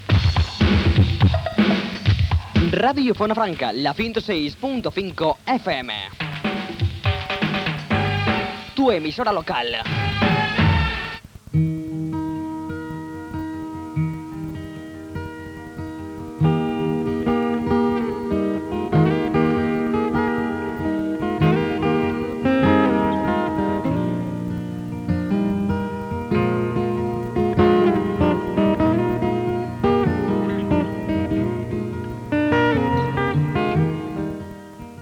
Indicatiu i música.